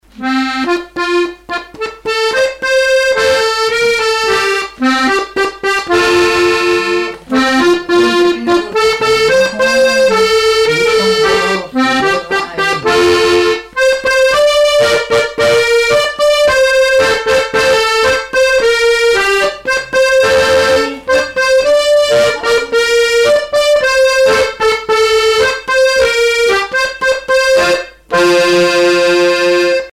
Répertoire du musicien sur accordéon chromatique
Pièce musicale inédite